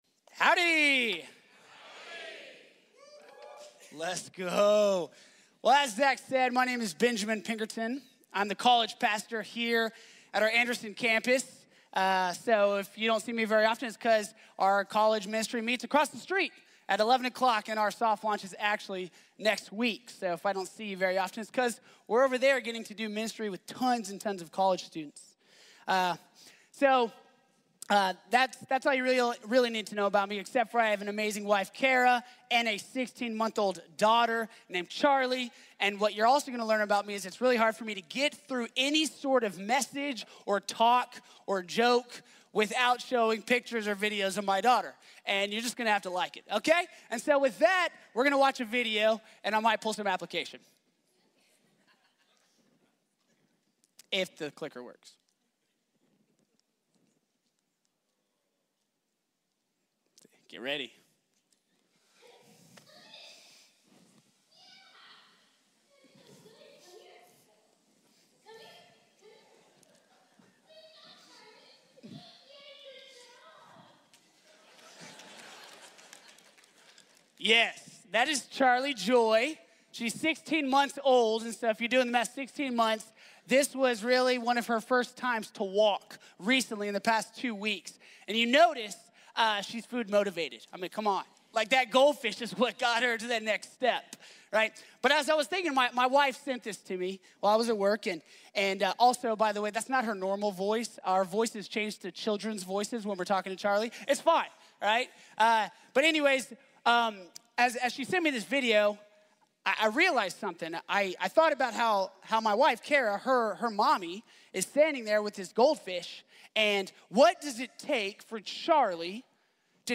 | Sermón de la Iglesia Bíblica de la Gracia